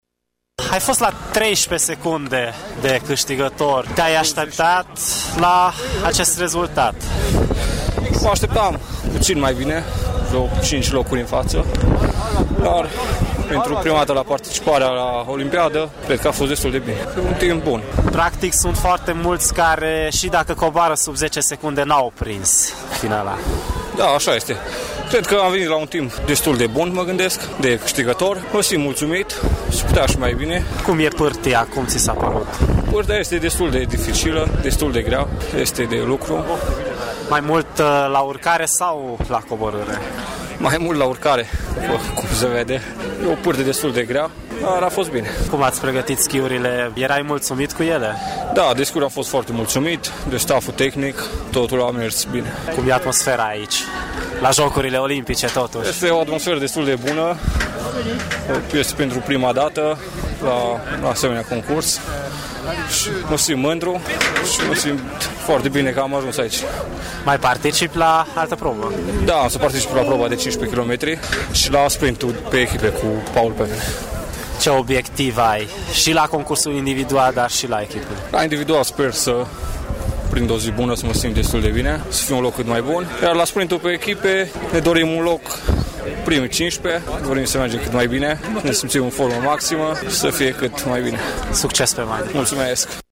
Un interviu
prezent în Coreea de Sud